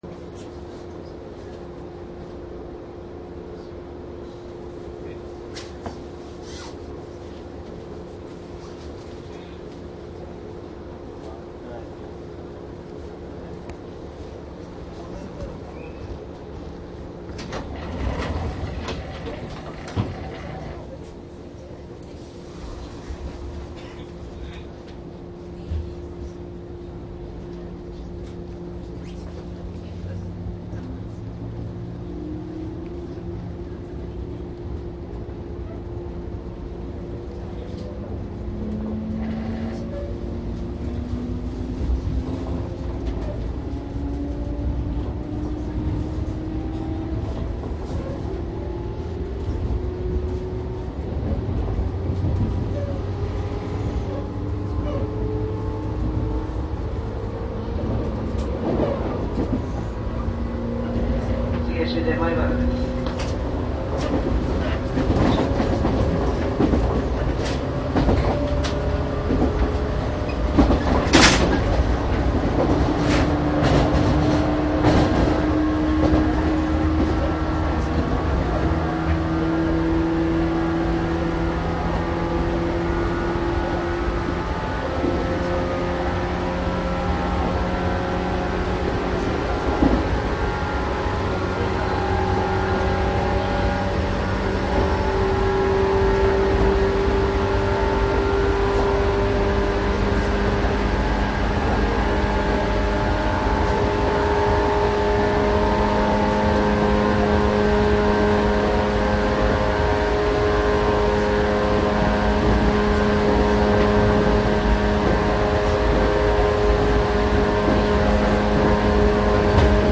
JR東海道線167系・113系 走行音CD
JR東海道線 の名古屋～米原で 167系と113系を録音しました。
いずれもマイクECM959です。TCD100の通常SPモードで録音。
実際に乗客が居る車内で録音しています。貸切ではありませんので乗客の会話やが全くないわけではありません。